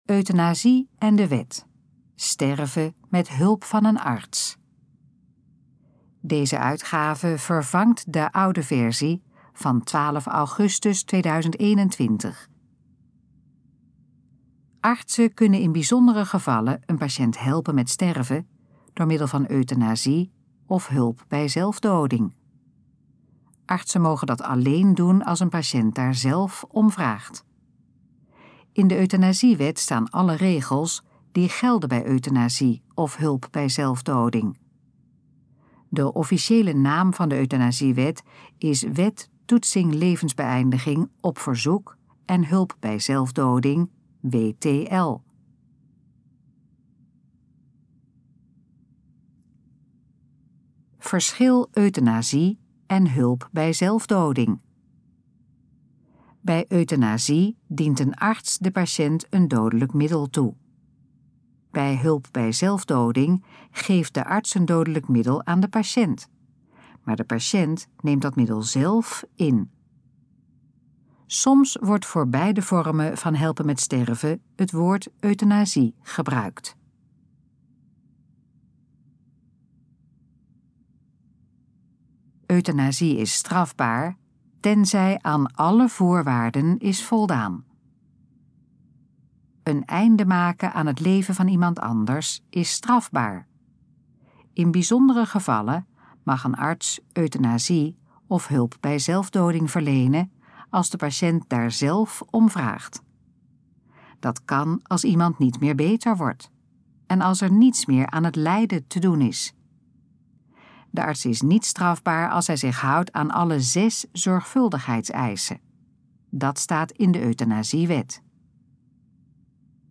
Gesproken versie van Euthanasie en de wet: sterven met hulp van een arts
Dit geluidsfragment is de gesproken versie van de informatie op de pagina Euthanasie en de wet: sterven met hulp van een arts.